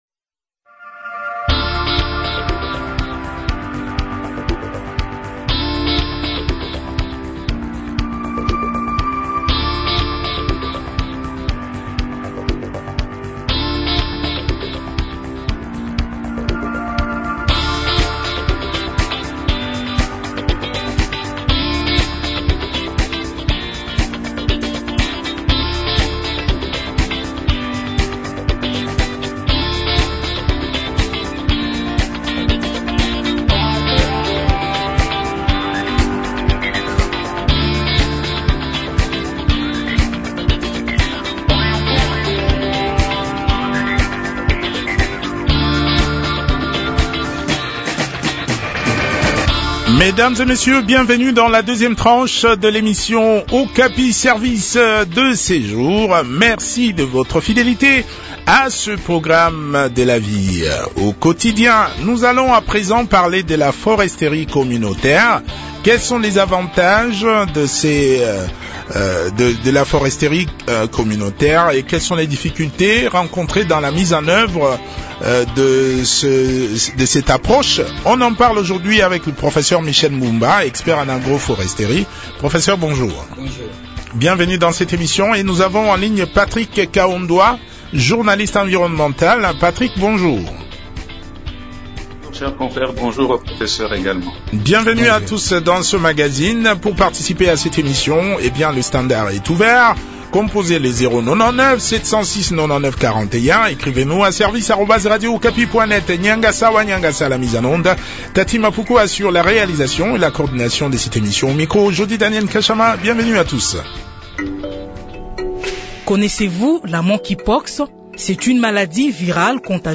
expert en agroforesterie a également participer à cet entretien.